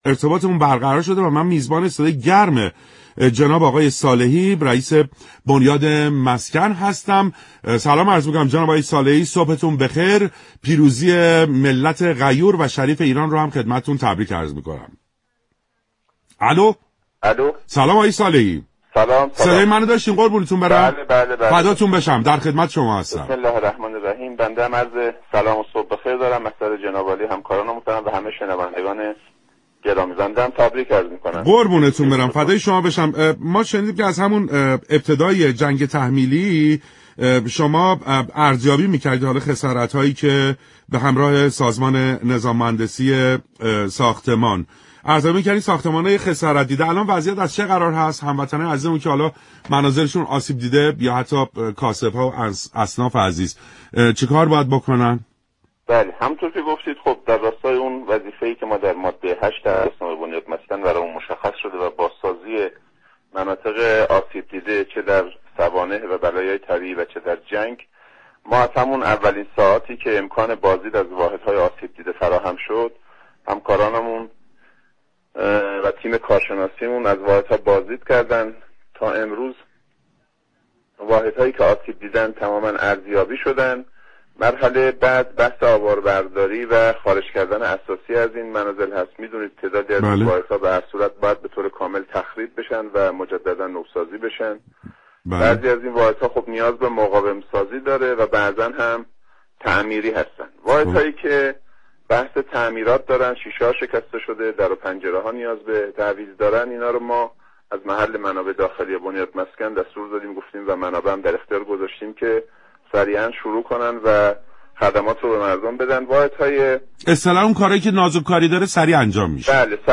رییس بنیاد مسكن در برنامه سلام صبح بخیر گفت: برخی از واحدها نیاز به تخریب و بازسازی دوباره و برخی دیگر نیاز به مقاوم‌سازی و تعمیر دارند.